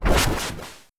Explosion6.wav